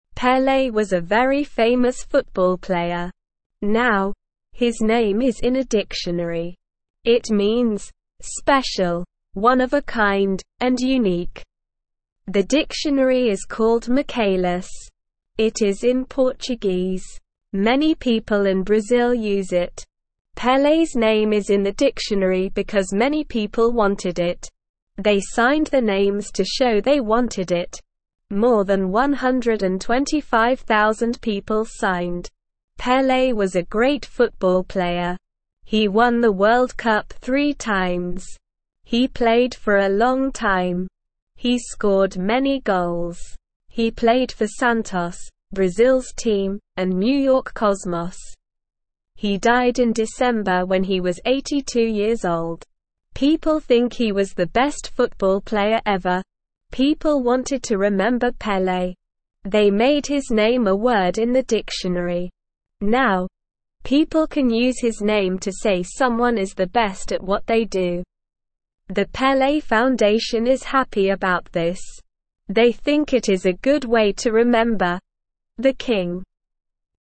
Slow
English-Newsroom-Beginner-SLOW-Reading-Famous-Football-Player-Pele-in-Dictionary.mp3